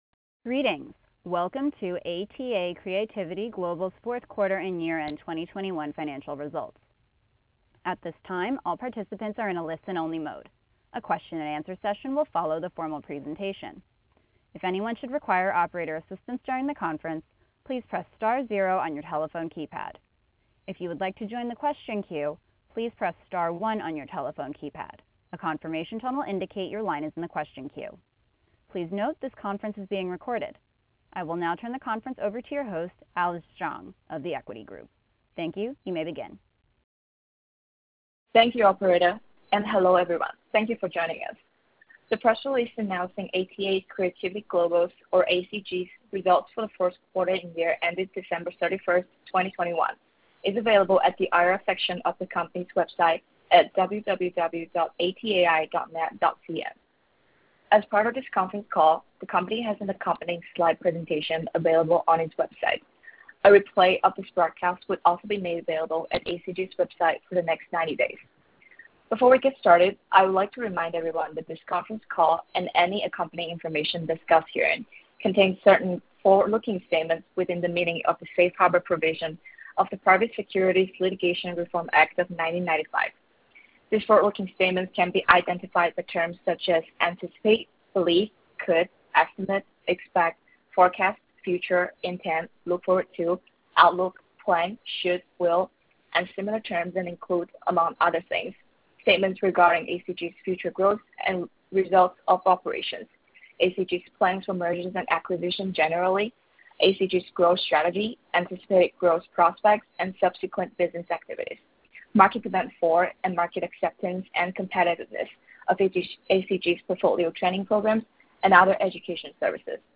Q4 Fiscal Year 2021 Earnings Conference Call